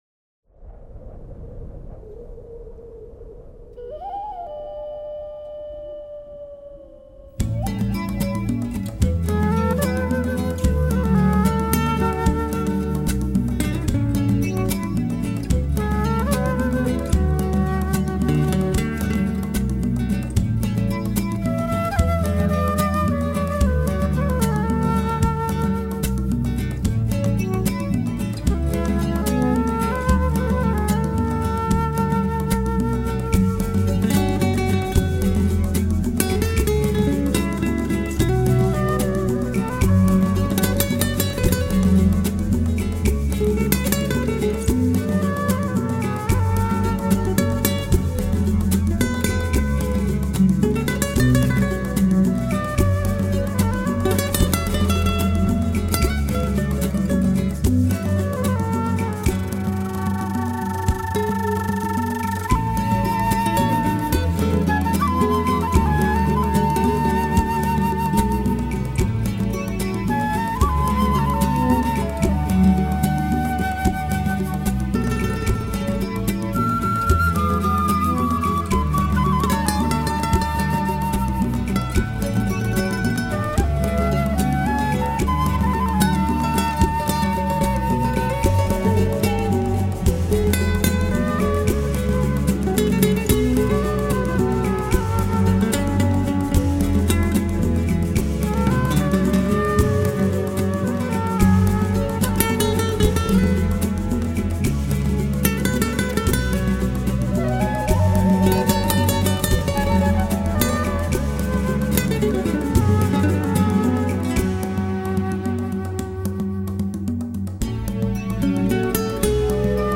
讓禿鷹飛越你的肩頭，閃電痛擊眼前枯樹，鼓群此起彼落重槌胸口，音場橫跨大荒漠